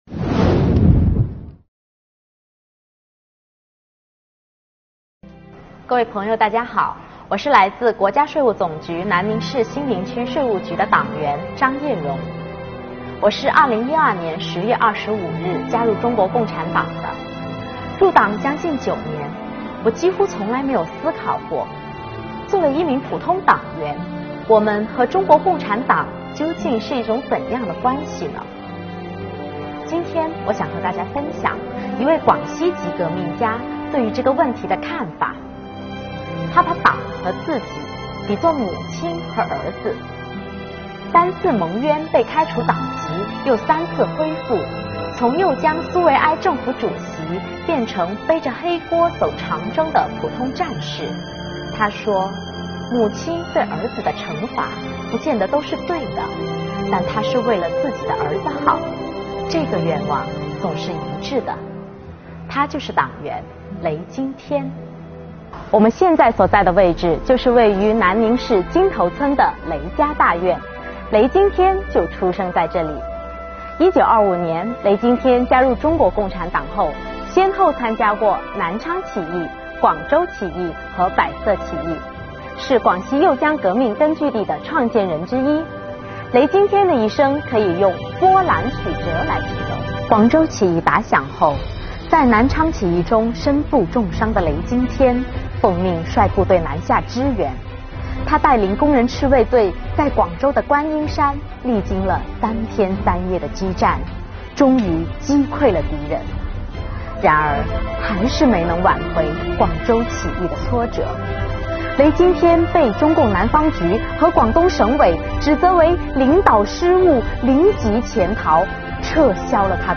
税干讲党史 | 党员雷经天：牢记入党初心